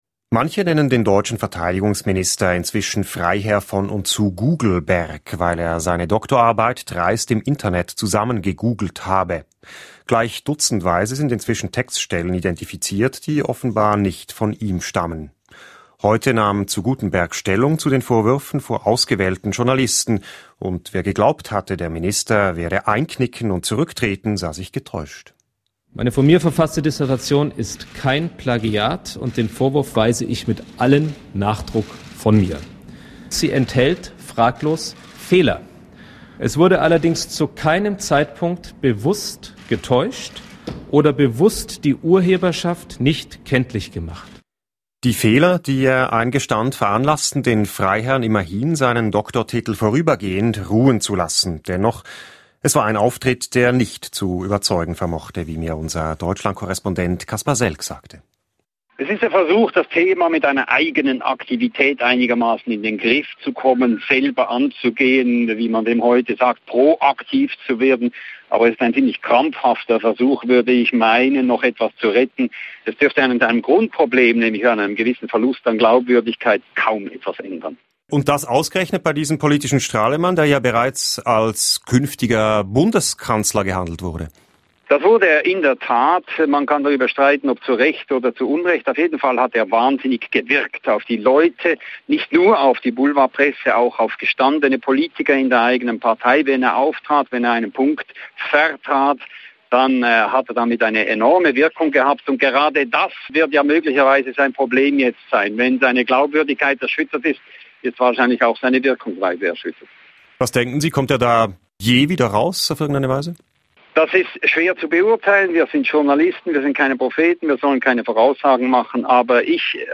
Karl-Theodor zu Guttenberg nahm vor Journalisten Stellung zu den Vorwürfen, seine Doktorarbeit im Internet abgeschrieben zu haben. Er räumt lediglich ein, es seien Fehler passiert.